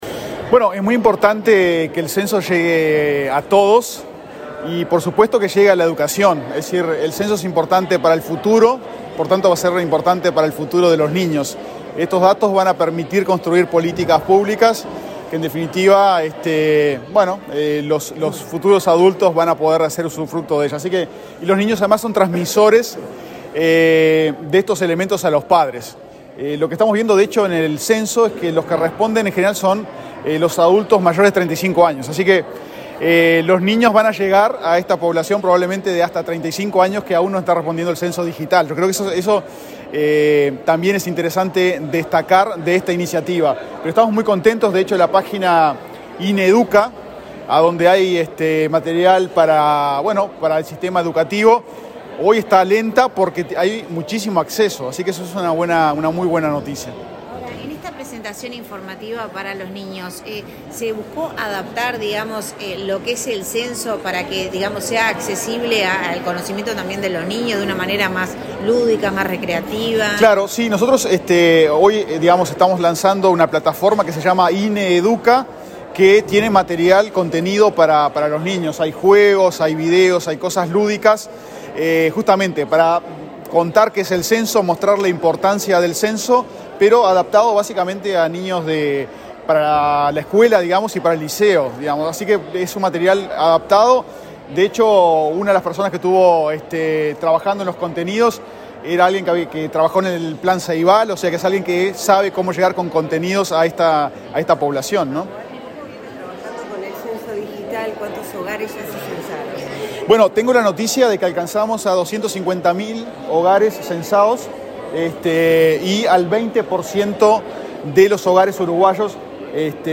Declaraciones del director del INE, Diego Aboal
El director del INE, Diego Aboal, dialogó con la prensa antes de participar del lanzamiento del Día Nacional del Censo 2023 en la educación, realizado